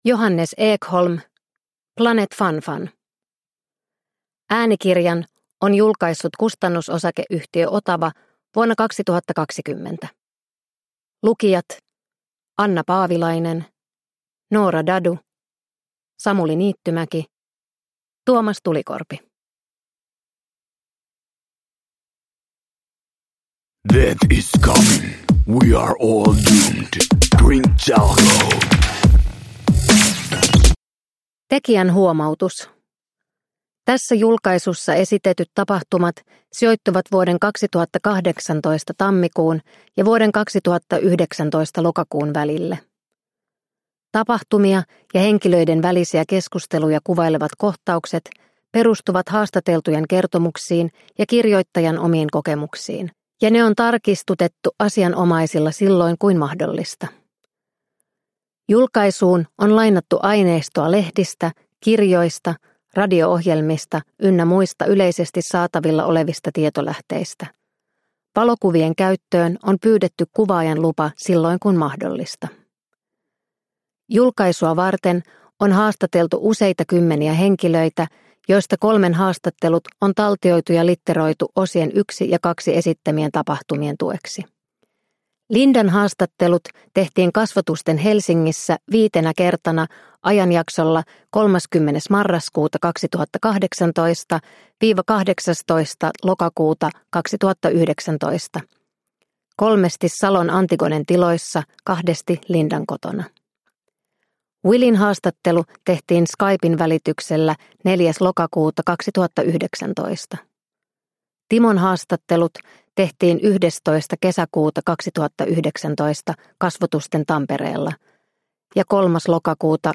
Planet Fun Fun – Ljudbok – Laddas ner